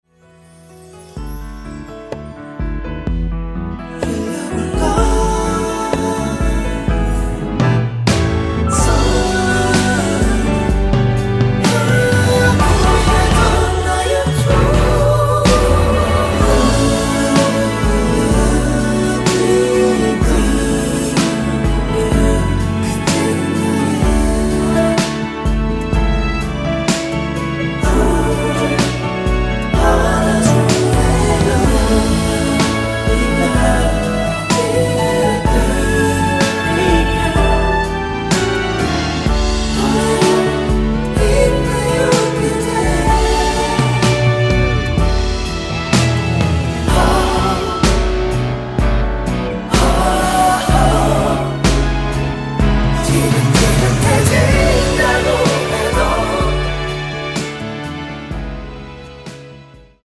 코러스 포함된 MR 입니다.
원키 코러스 포함된 MR 입니다.(미리듣기 참조)
앞부분30초, 뒷부분30초씩 편집해서 올려 드리고 있습니다.
중간에 음이 끈어지고 다시 나오는 이유는